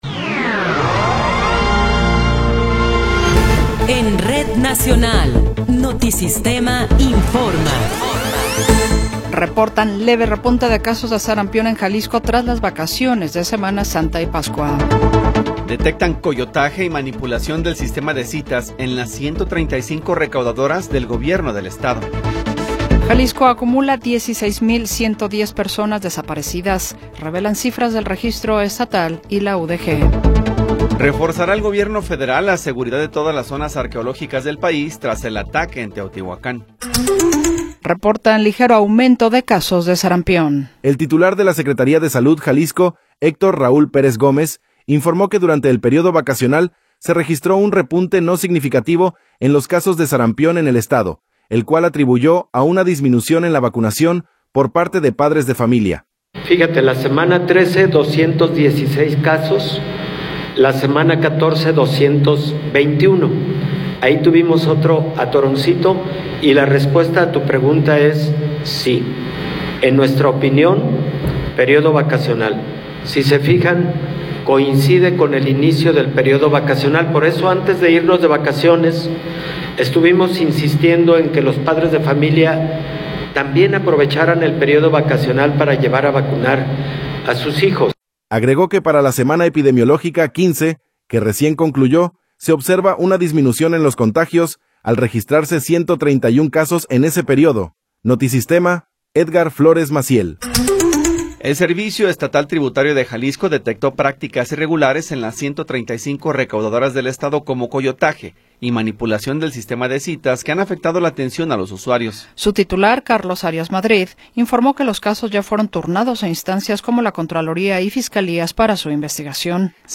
Noticiero 14 hrs. – 21 de Abril de 2026
Resumen informativo Notisistema, la mejor y más completa información cada hora en la hora.